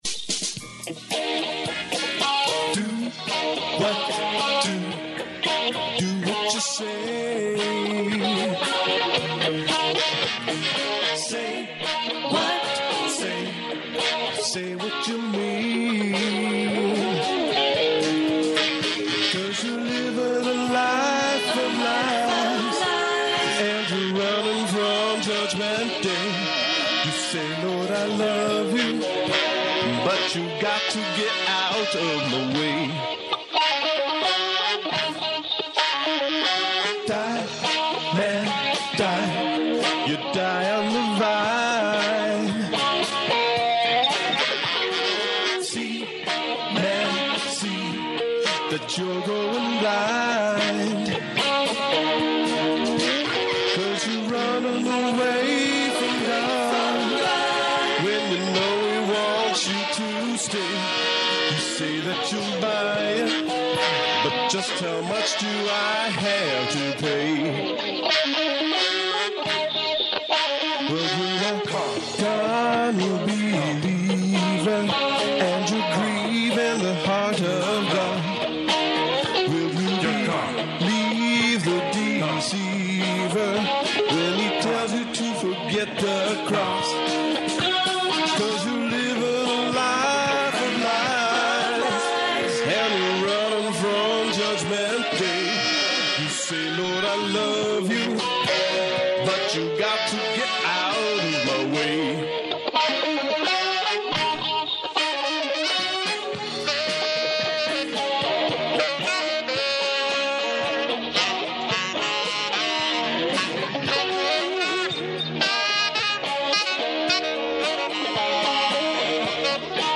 I digitized all the recordings from scratchy and warbly cassette tapes that sat in a box for decades.